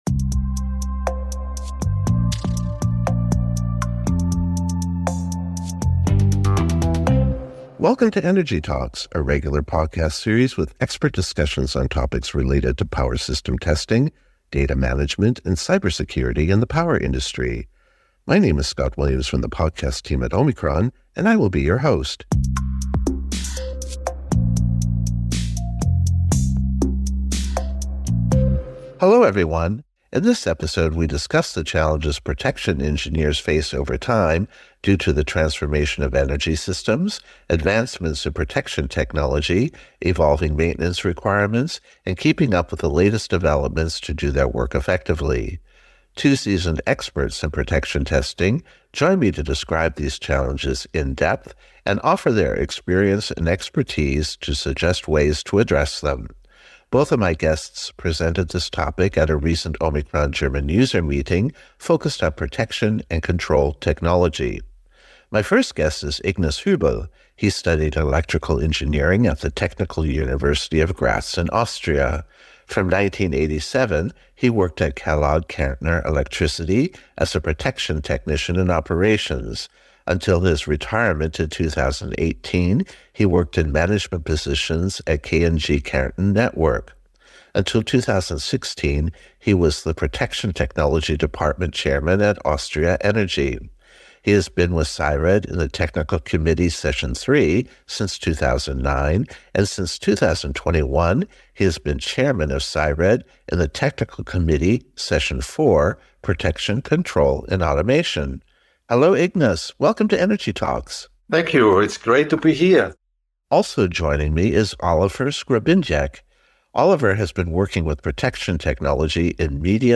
Two seasoned protection testing experts describe these challenges and suggest ways to address them based on their experience and expertise.